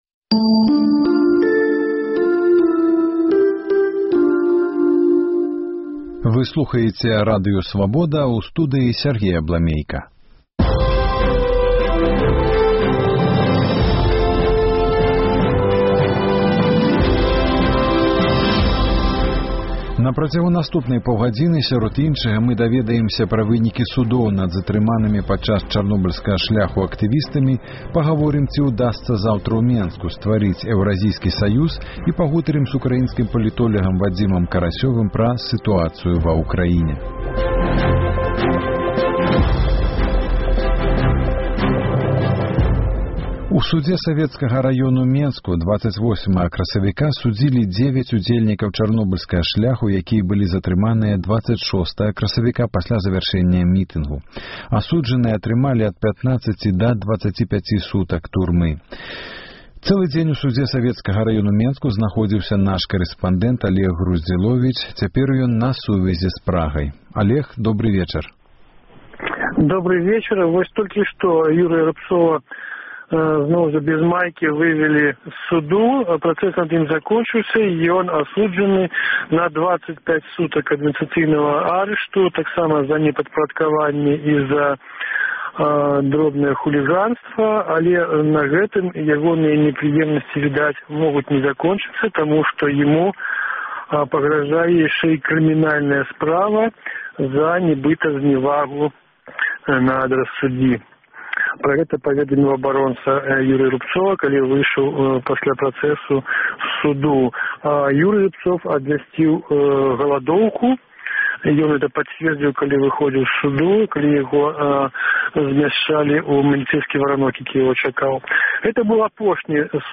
Як у Кіеве камэнтуюць новыя санкцыі і чаму прабуксоўвае антытэрарыстычная апэрацыя на ўсходзе Ўкраіны? Пра гэта – гутарка